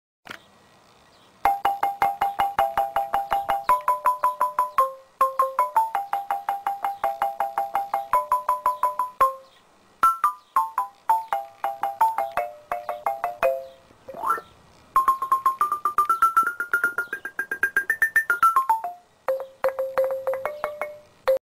xilofono.mp3